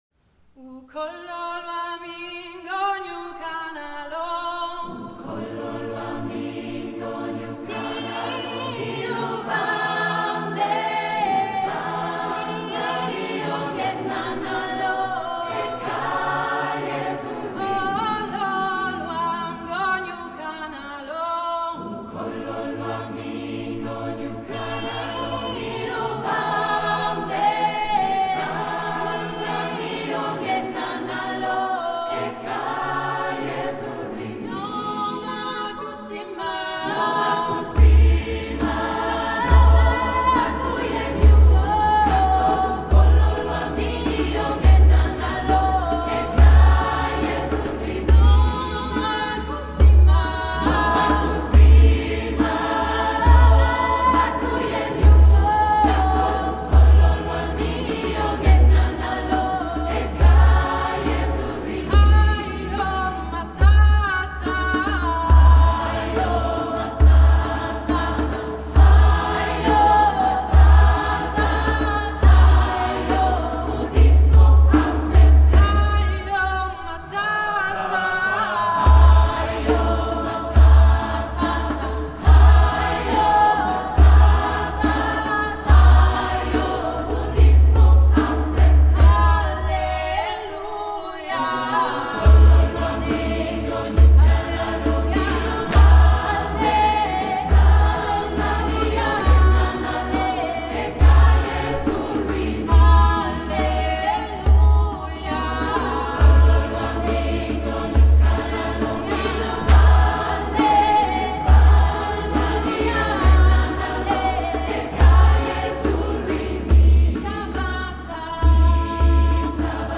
Tëuta su ai 27.04.2025 tla dlieja de Urtijëi